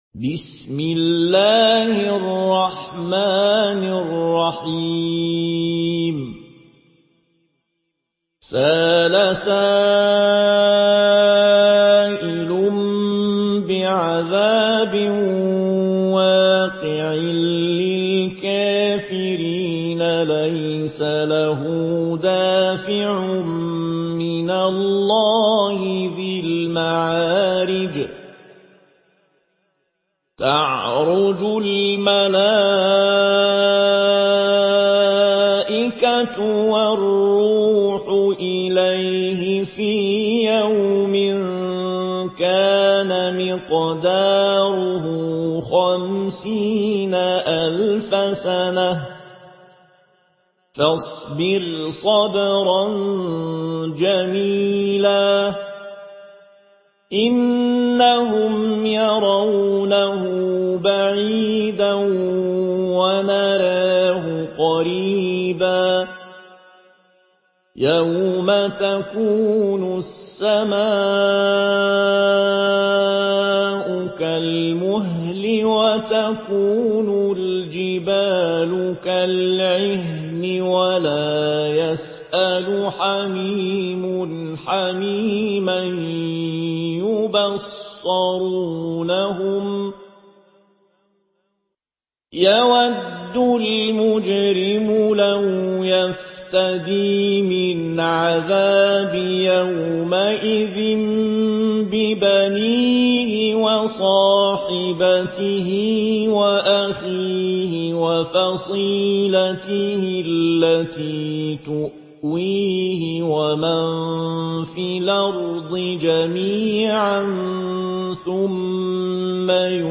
Une récitation touchante et belle des versets coraniques par la narration Warsh An Nafi.
Murattal Warsh An Nafi